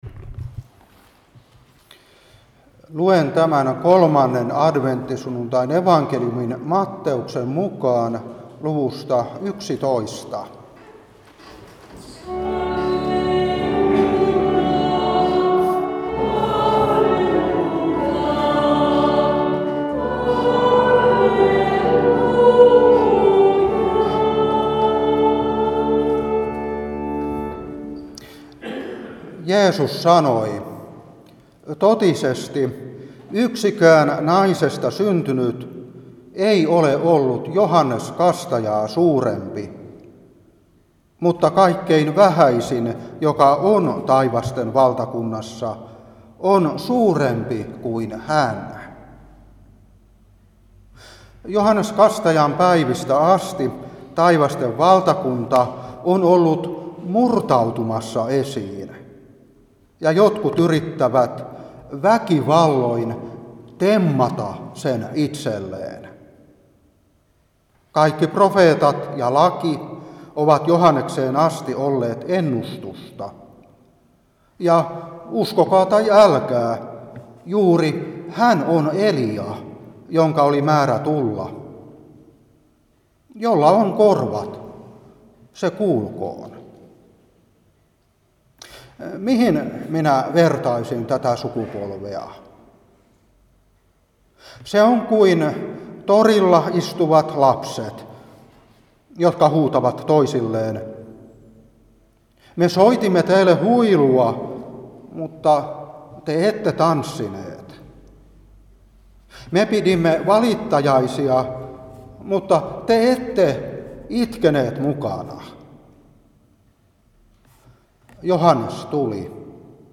Saarna 2022-12.